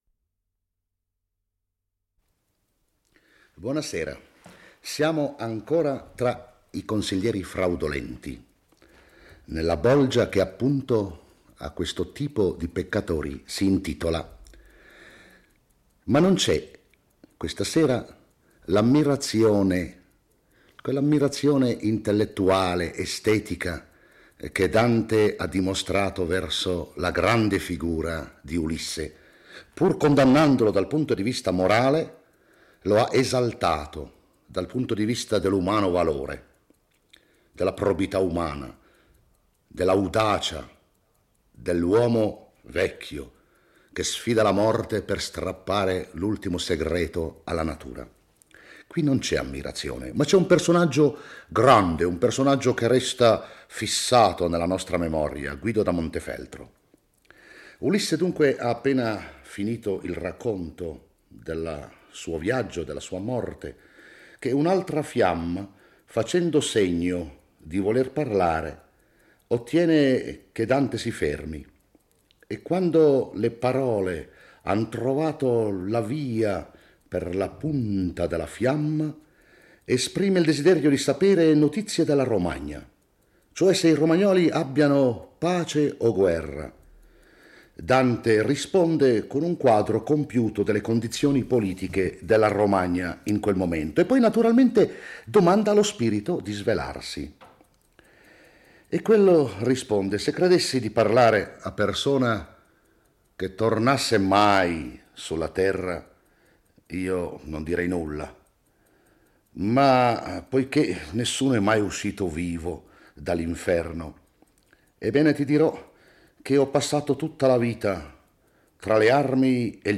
legge e commenta il XXVII canto dell'Inferno